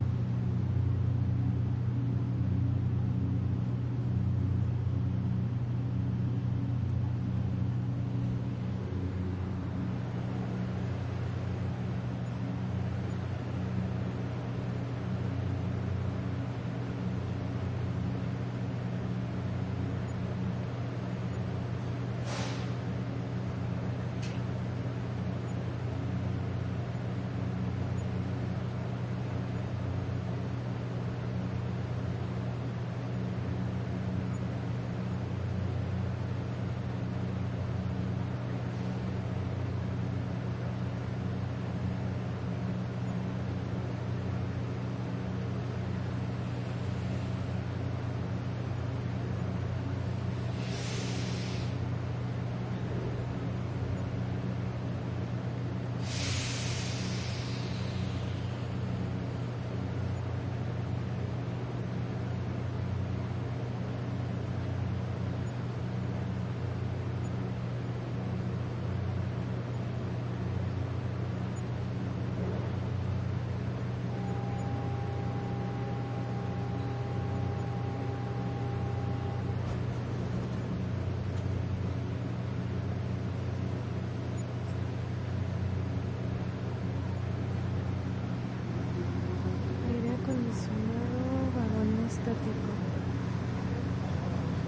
地铁 " 等待地铁
描述：在地铁站等待火车
标签： 地铁 地铁 火车
声道立体声